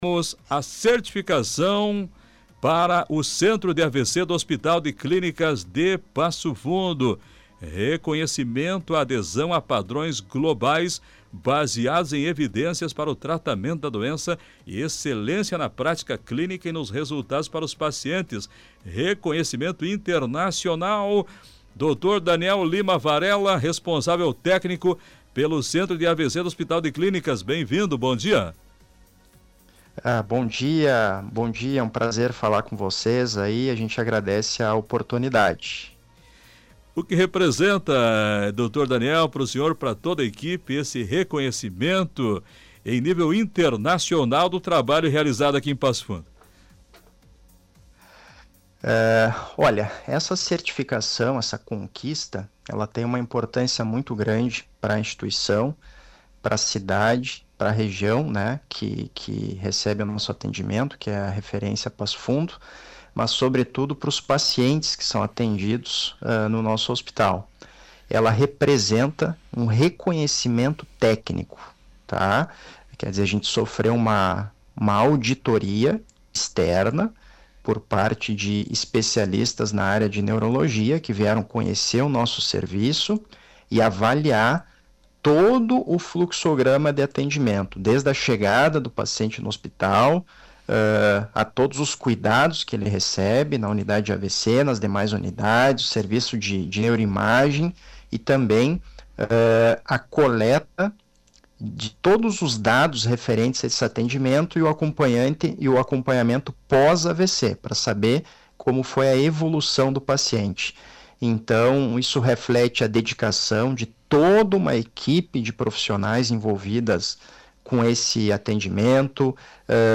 Entrevista: Centro de AVC do Hospital de Clínicas conquista certificação internacional